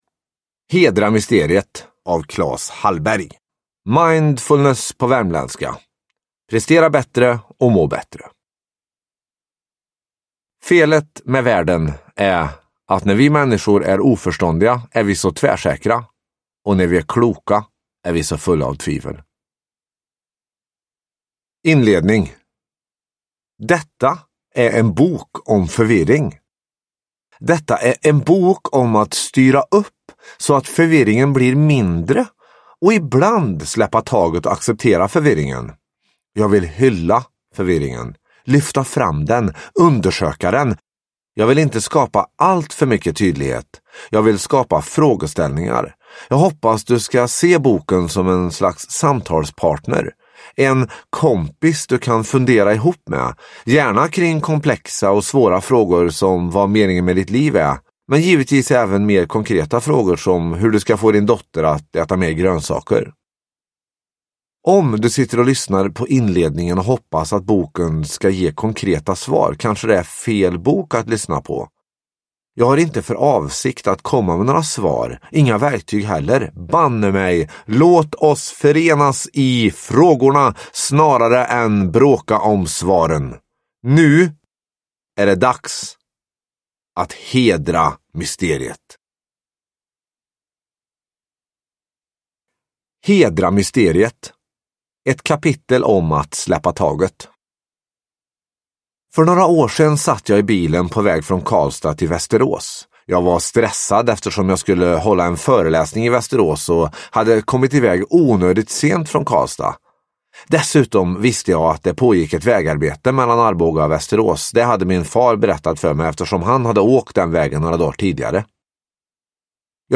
Hedra mysteriet – Ljudbok – Laddas ner